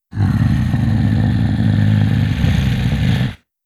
Monster Roars
18. Rumbling Growl.wav